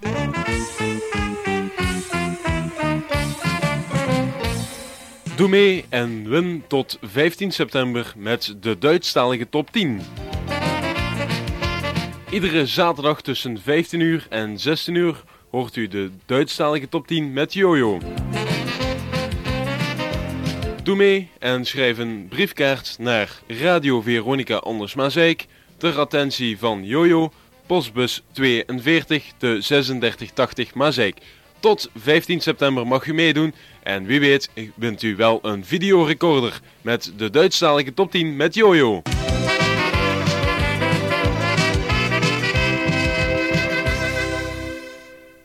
Reclamespot verloting Duitstalige Top 15.mp3